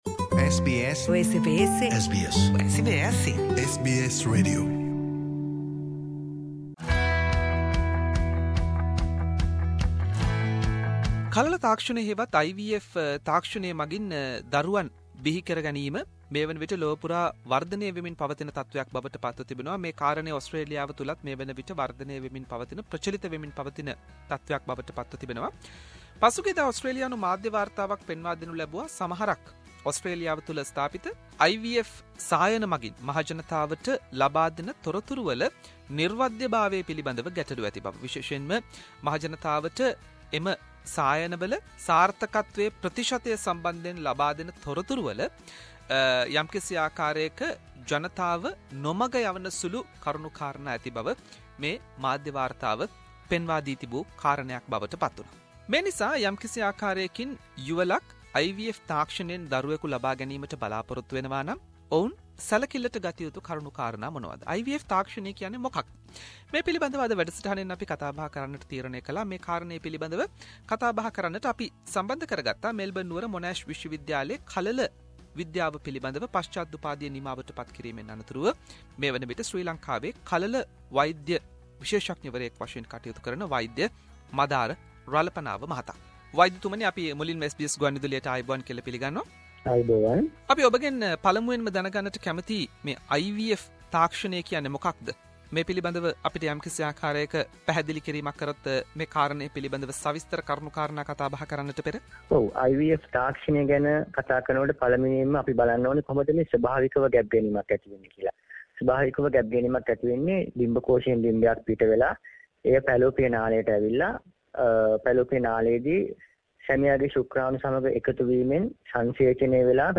Recently Australian IVF industry criticised for using misleading claims and aggressive marketing. In this interview we have discussed Things you need to know before going to an IVF clinic, with Clinical Embryologist